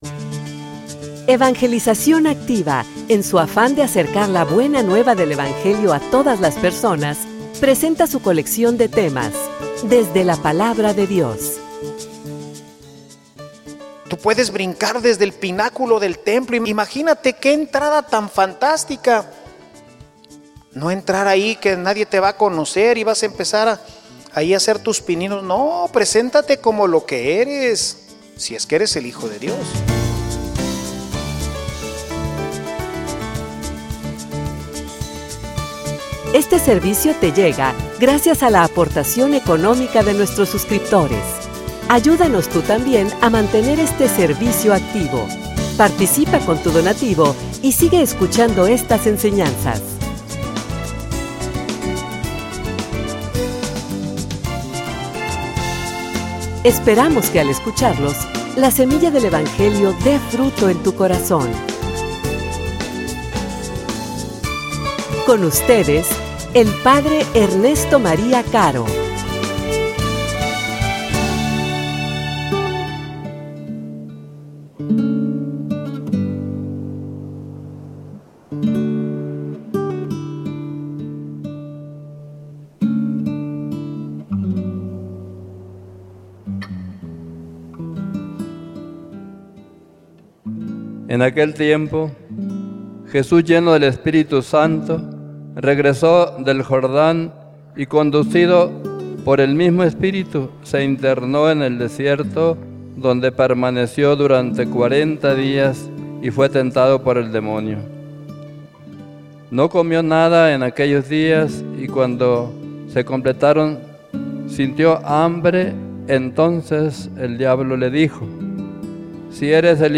homilia_Tu_adversario_no_descansa.mp3